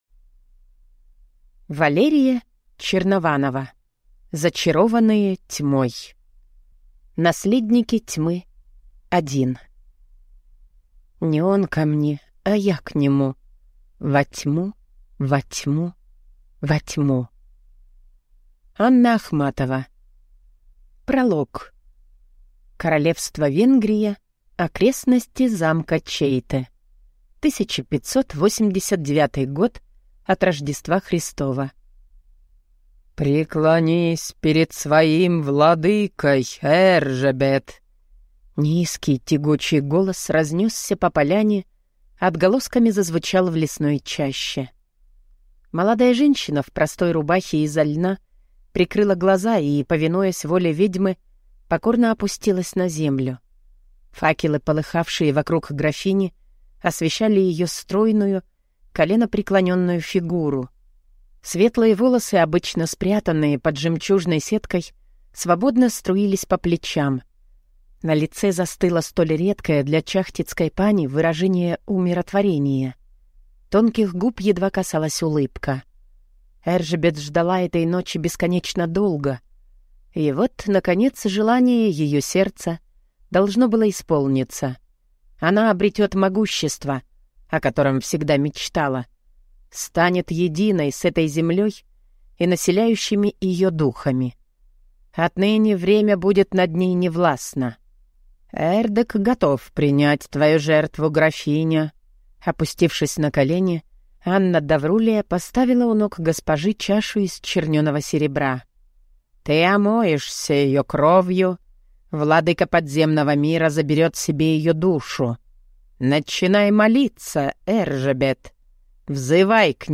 Aудиокнига Зачарованная тьмой